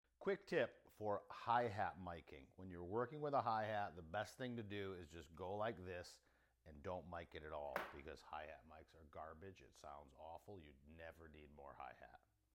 This hi hat microphone technique will make your mixes sound so much better.